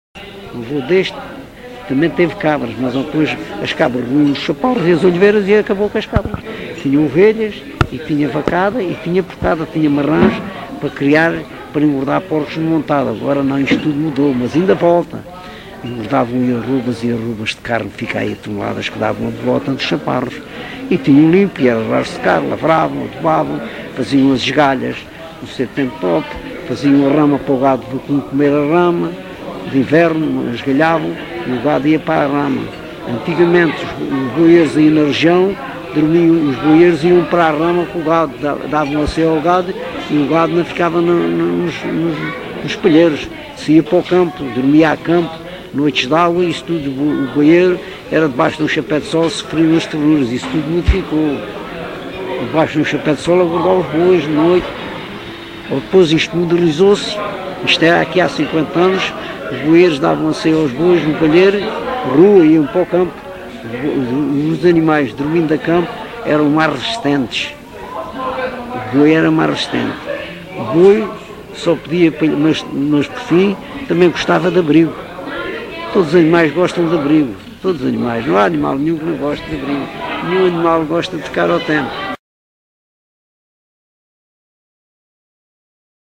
LocalidadeCouço (Coruche, Santarém)